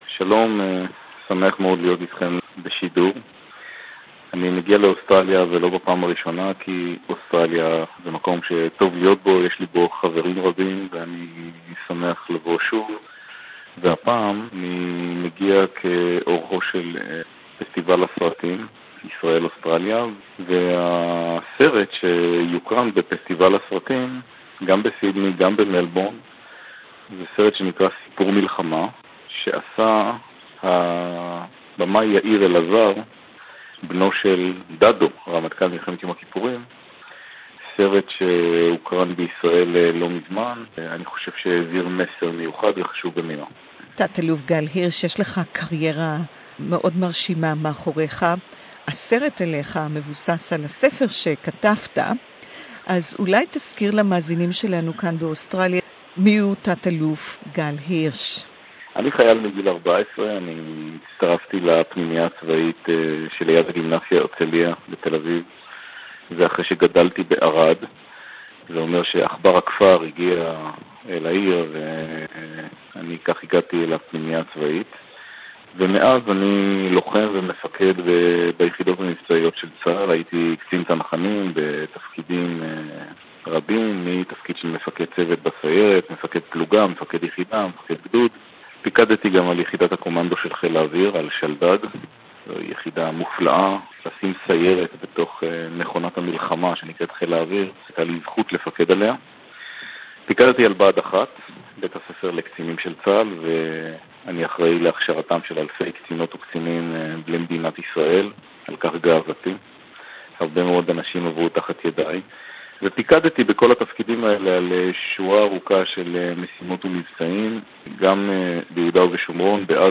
Brigadier General Gal Hirsch, Glimpse into the life & career of a legendary Israeli hero, Hebrew Interview